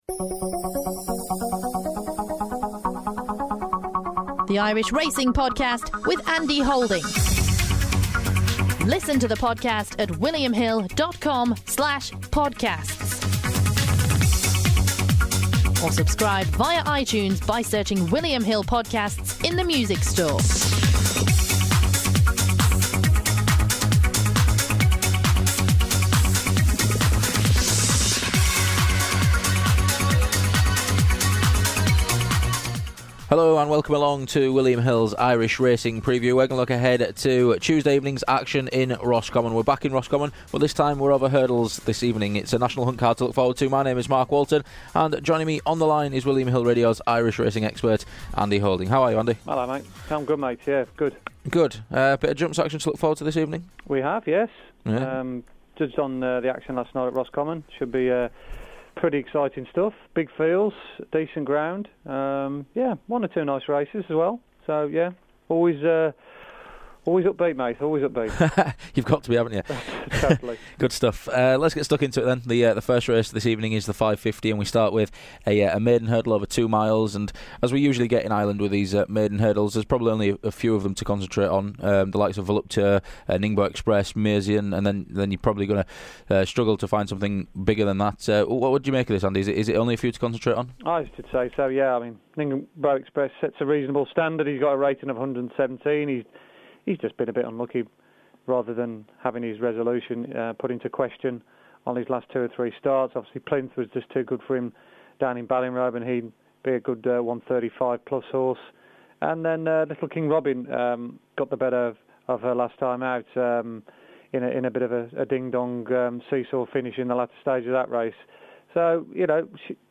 is joined on the line